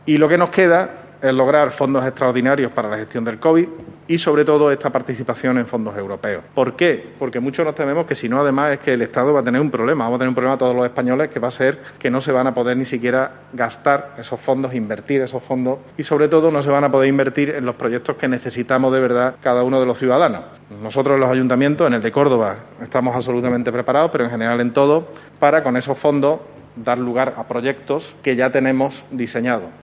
En una rueda de prensa, el regidor ha manifestado que "igual que el resto de compañeros alcaldes de toda España, reclamamos fondos europeos", algo en lo que "la semana pasada ya la Comisión Europea dio la razón a los alcaldes, diciendo que la participación de los ayuntamientos en la gestión de fondos europeos es fundamental y necesaria", pero "hasta ahora eso no se ha traducido en medidas por parte del Gobierno", ha lamentado.